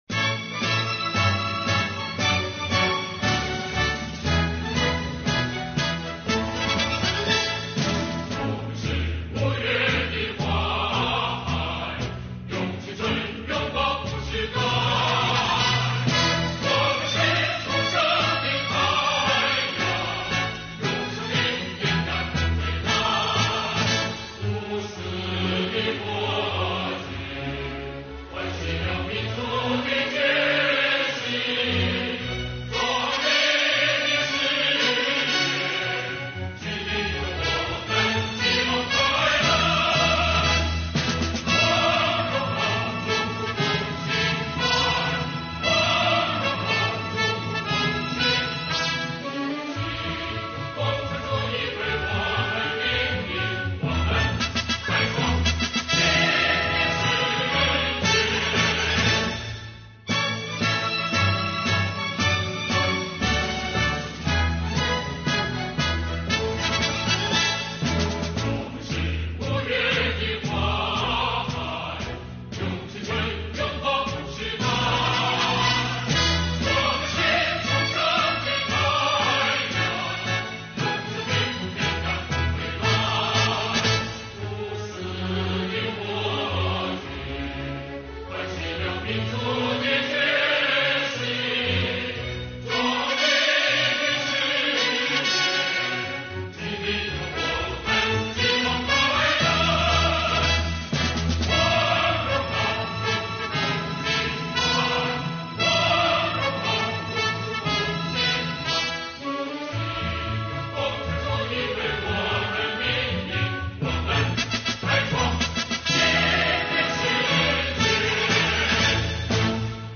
[中国税务报] 山东税务青年干部齐唱团歌 继承发扬五四精神